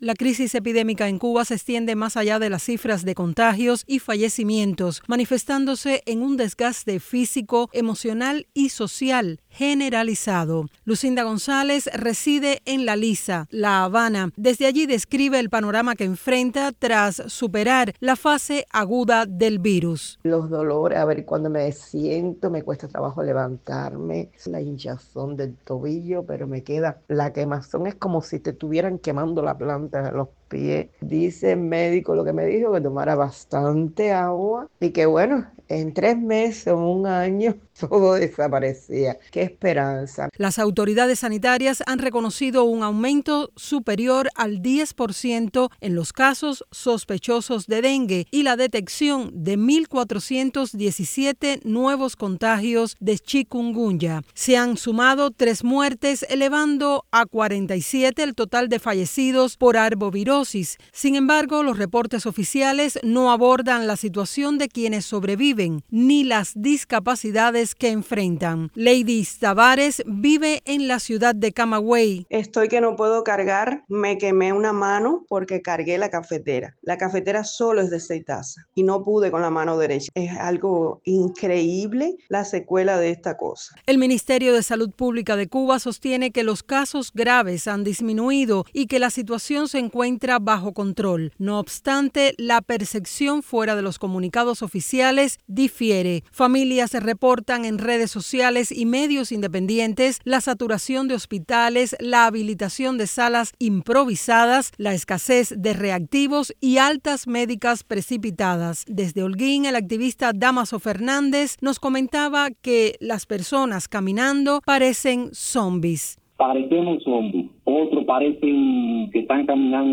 Las secuelas de los virus en Cuba: testimonios